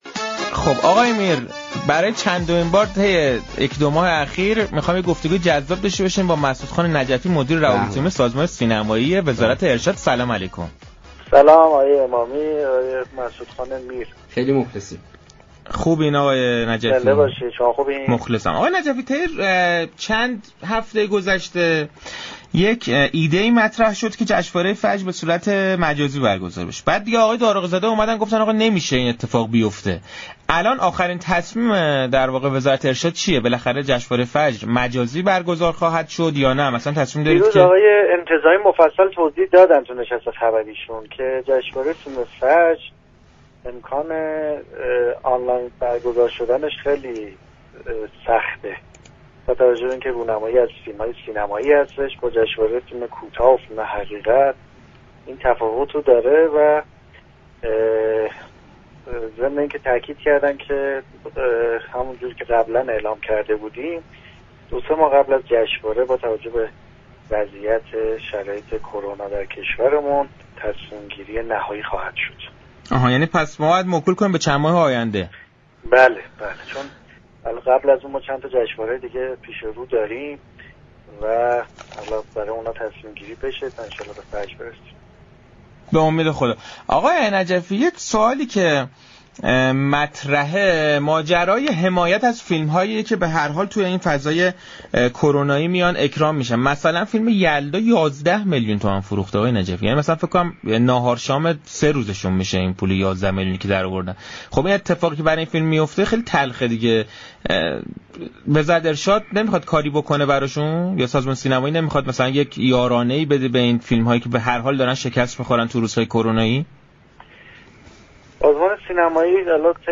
در گفتگو با صحنه رادیو تهران